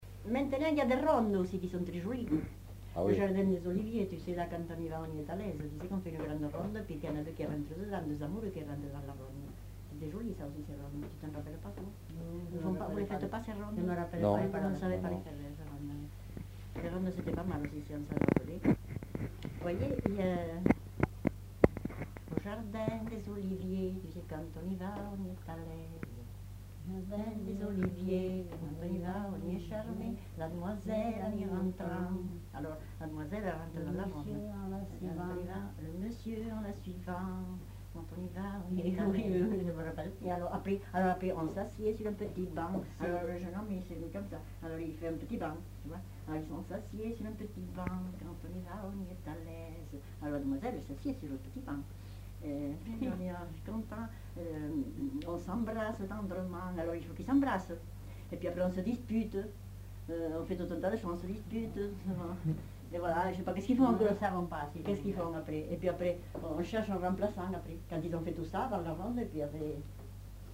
Aire culturelle : Rouergue
Genre : chant
Effectif : 2
Type de voix : voix de femme
Production du son : chanté
Danse : ronde
Notes consultables : Chante et explique la danse au fur et à mesure.